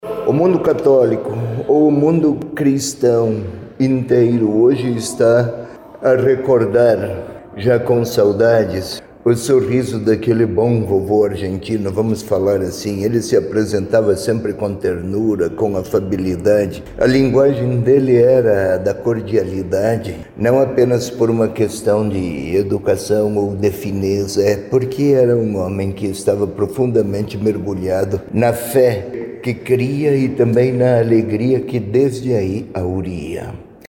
Dom José Peruzzo disse que o mundo cristão recordou e fez homenagens, neste domingo, para o Papa Francisco.